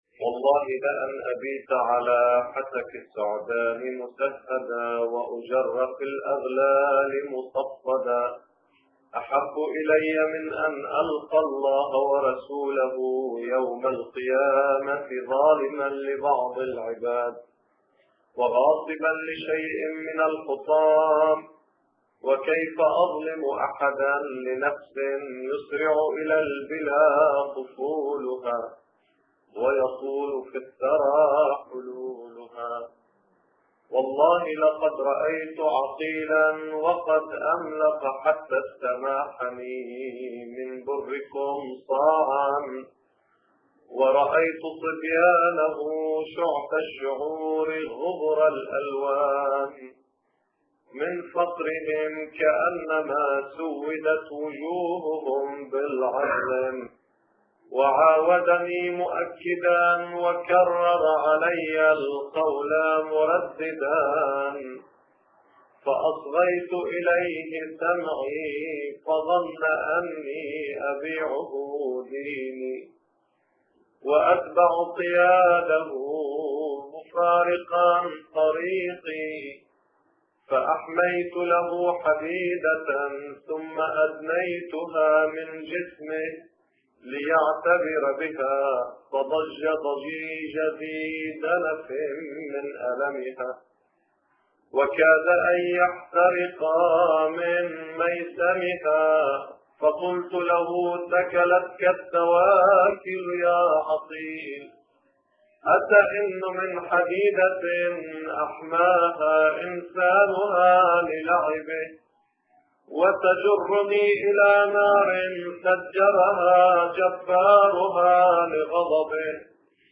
مدائح